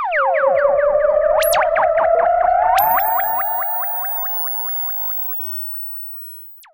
Theremin_Swoop_09.wav